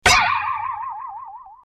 Funny Boing Spring Sound Comedy - Botão de Efeito Sonoro